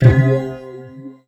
41 SYNT01 -L.wav